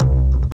Bass (5).wav